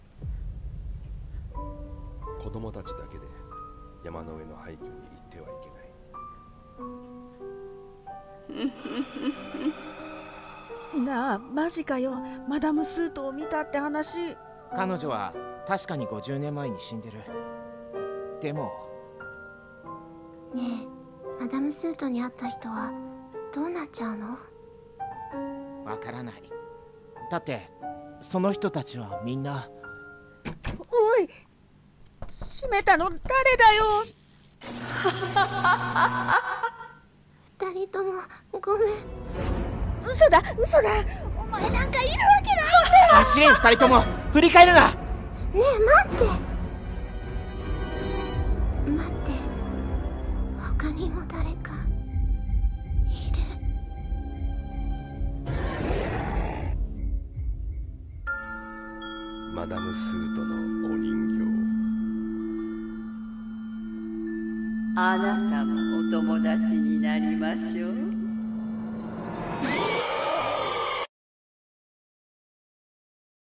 【動画になる声劇】煤婦人のお人形【映画CM】